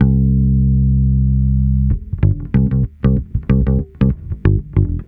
Track 13 - Bass 02.wav